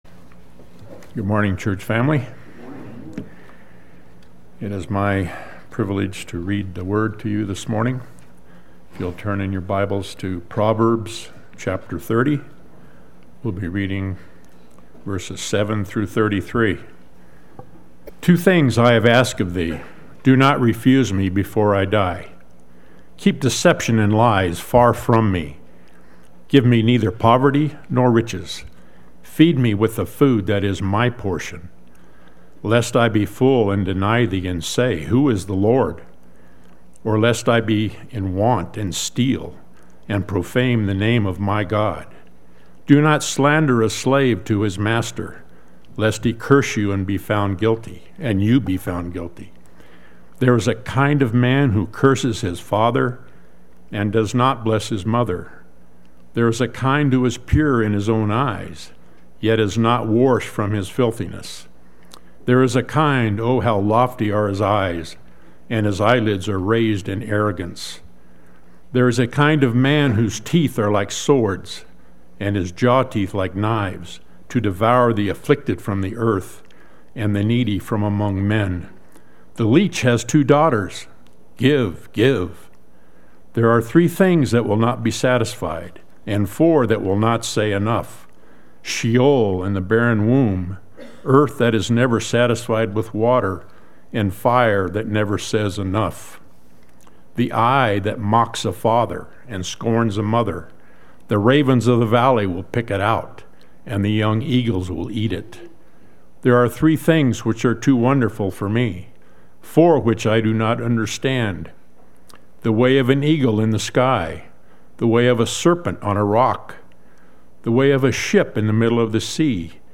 Play Sermon Get HCF Teaching Automatically.
The Name of My God Sunday Worship